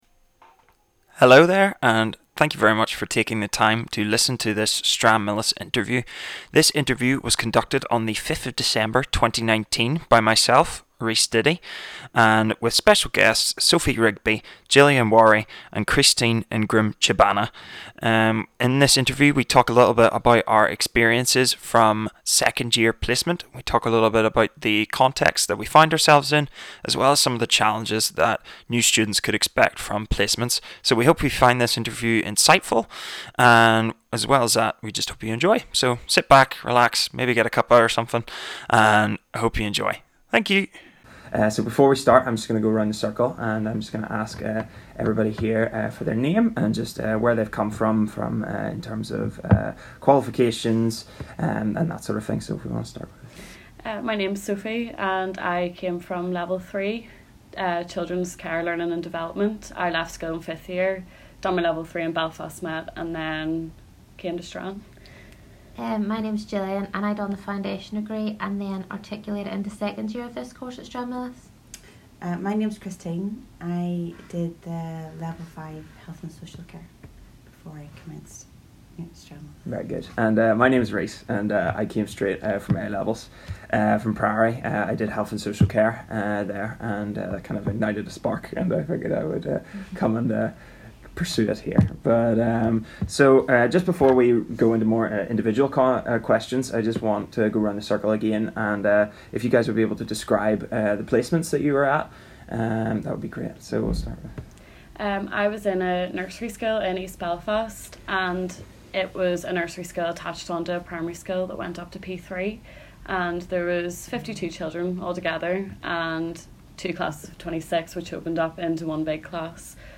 Listen to what some of ECS students have to say about their placement experiences.